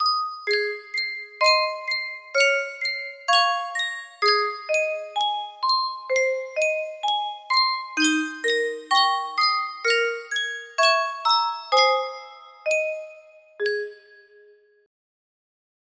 Sankyo Music Box - Oh Promise Me GGZ music box melody
Full range 60